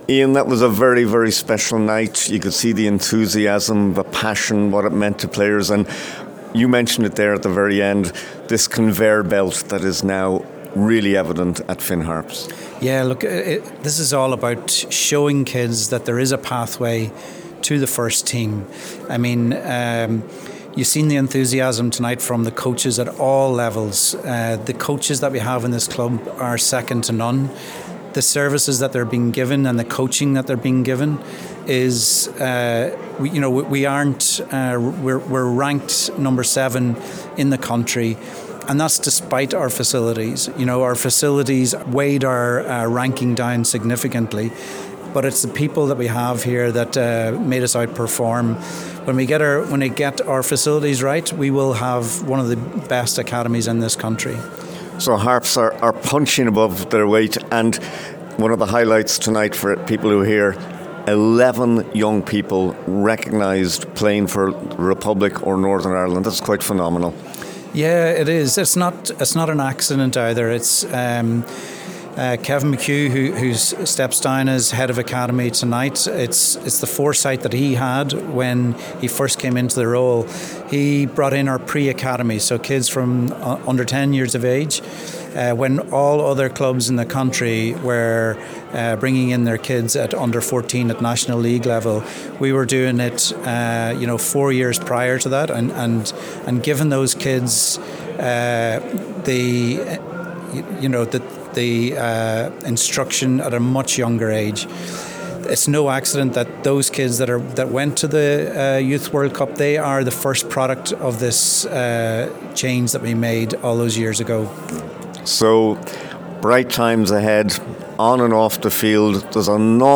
during the event